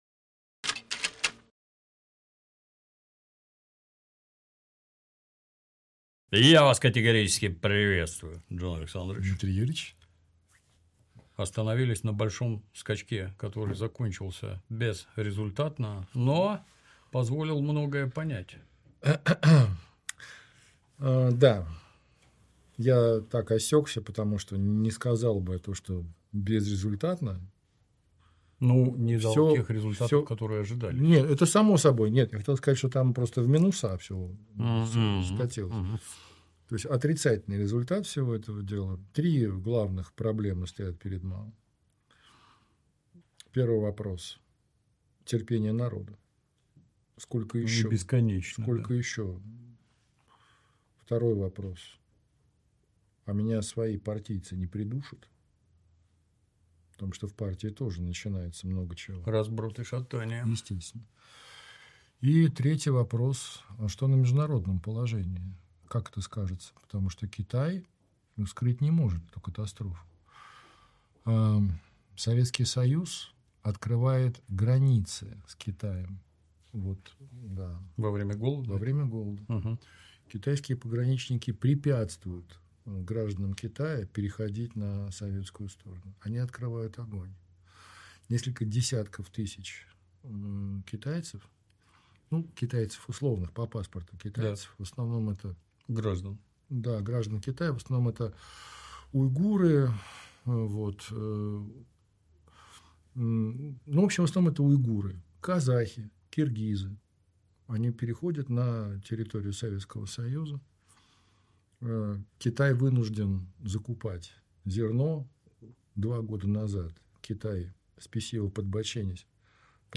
interview_kitai03.mp3